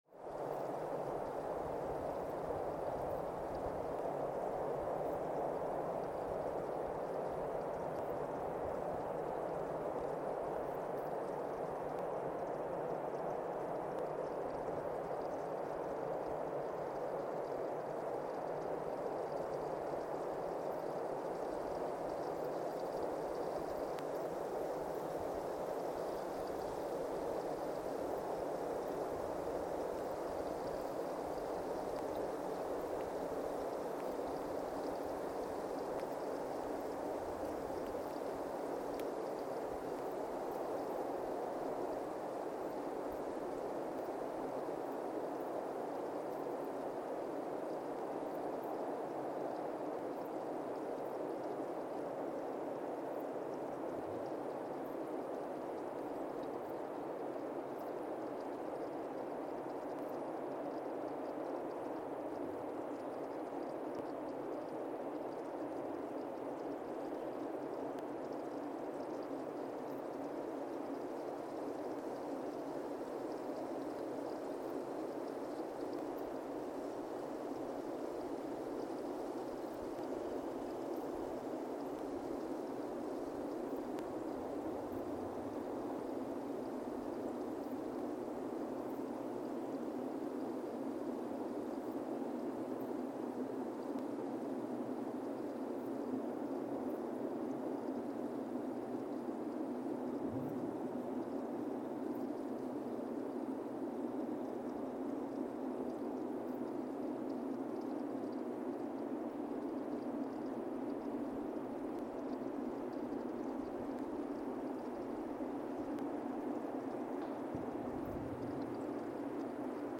Weston, MA, USA (seismic) archived on December 12, 2024
Station : WES (network: NESN) at Weston, MA, USA
Sensor : CMG-40T broadband seismometer
Speedup : ×1,800 (transposed up about 11 octaves)
Loop duration (audio) : 05:36 (stereo)